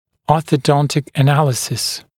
[ˌɔːθə’dɔntɪk ə’næləsɪs][ˌо:сэ’донтик э’нэлэсис]ортодонтический анализ